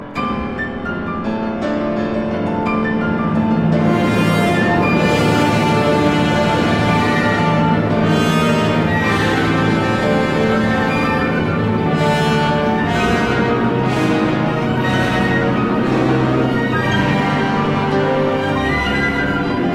Version choisie pour les extraits : prestation de Anna Vinnitskaya et l'Orchestre National de Belgique sous la direction de Gilbert Varga lors de la finale du Concours Reine Elisabeth et avec lequel elle remporta le 1er Prix en 2007.
Ecoutons cette cadence qui occupe presque la moitié du mouvement, unique d'invention harmonique et technique, qui va aller dans un crescendo d'intensité, de mp dolce à fff pesante avec des cascades d'accords, arpèges, piano exploité dans toute sa tessiture, mélodie aux pouces au sein de traits fulgurants, glissandi, agrémentés d'indications multiples telles fff precipitato, pesante, con effetto, fff colossale, et se clôturant tutta forza, sur lequel revient l'orchestre (mes. 183 env. 10'13'') et le poids massif de ses cuivres au plus fort du déchaînement pianistique, avec le motifs des deux premières mesures ouvrant le concerto.